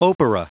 Prononciation du mot opera en anglais (fichier audio)